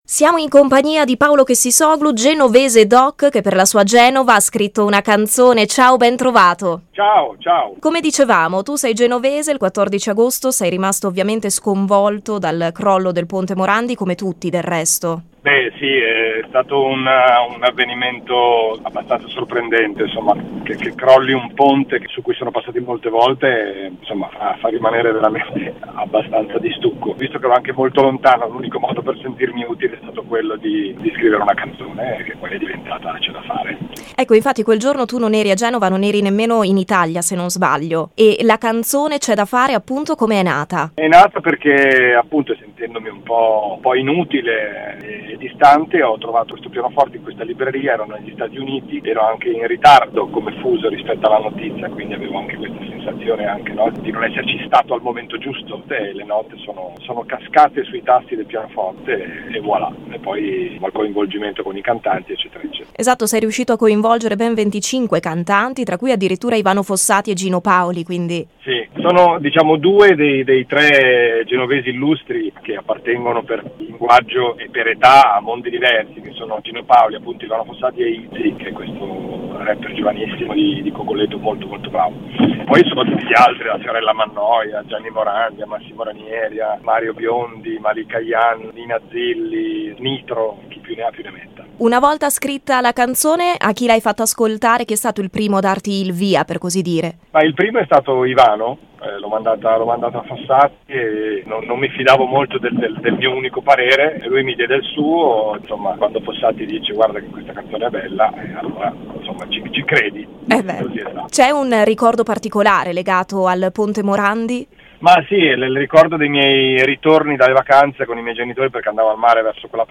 Ne ha parlato al microfono